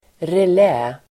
Ladda ner uttalet
Uttal: [rel'ä:]